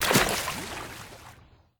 default_water_footstep.2.ogg